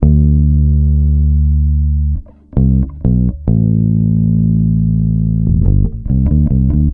C BASS 2.wav